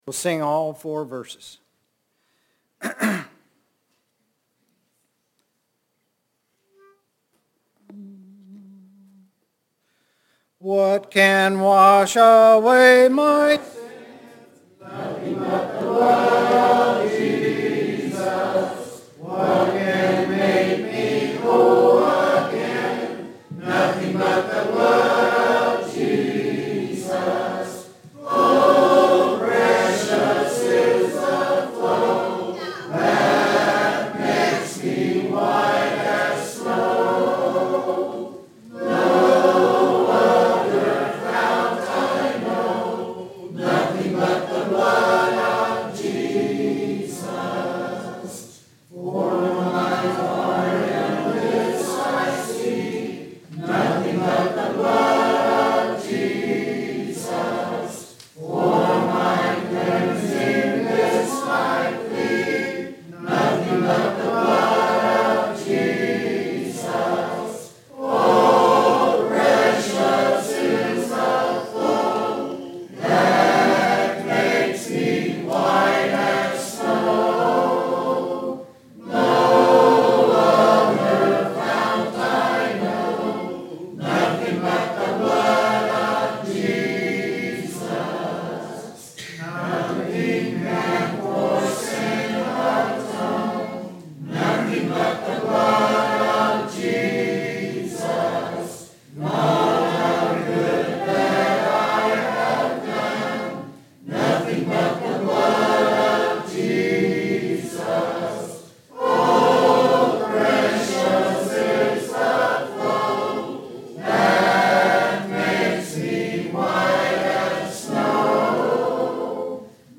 Sun PM Worship10.30.22 – Fifth Sunday Singing